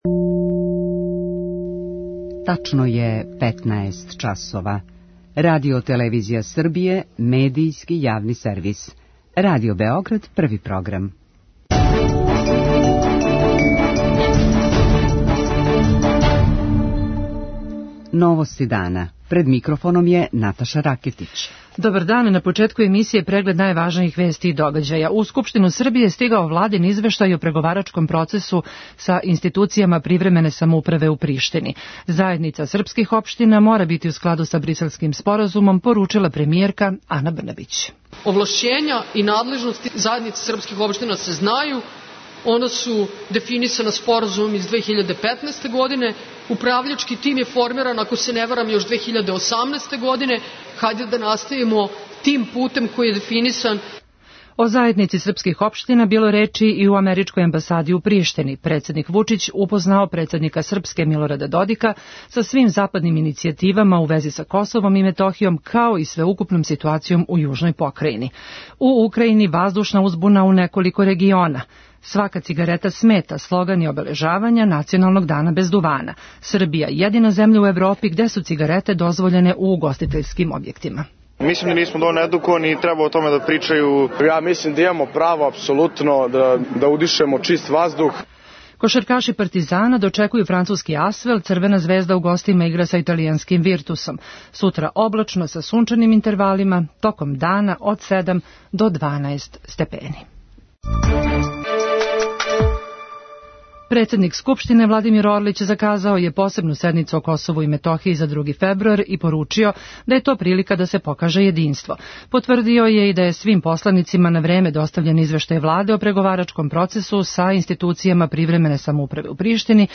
novosti3101.mp3